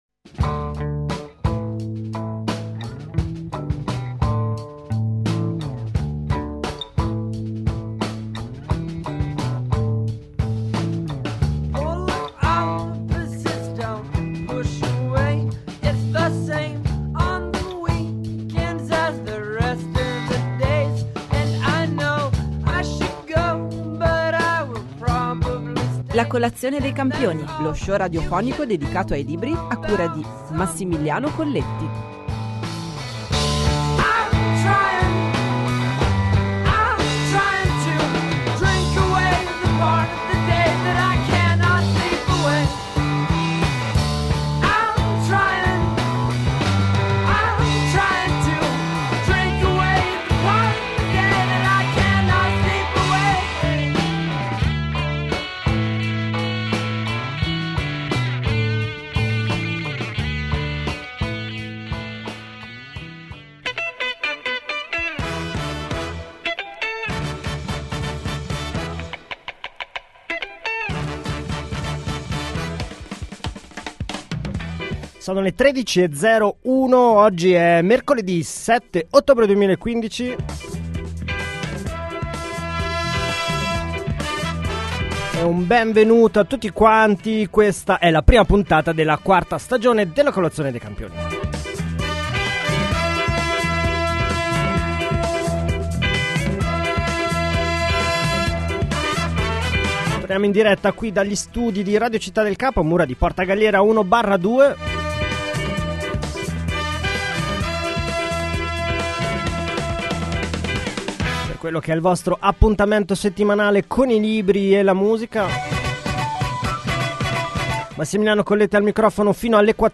Torna per la quarta stagione La colazione dei campioni, lo show radiofonico dedicato ai libri e alla musica di Radio Città del Capo.